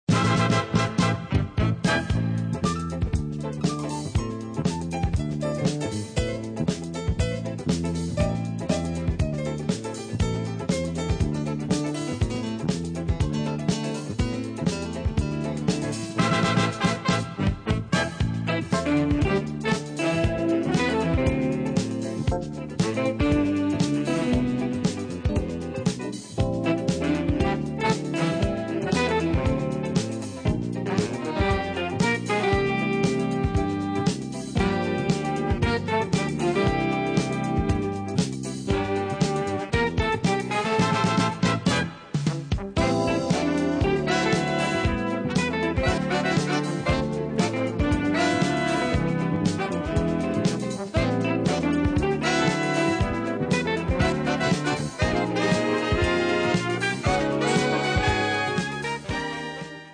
alto sax, flute
tenor sax
baritone sax
trumpets
trombone
tuba
guitar
piano
bass
drums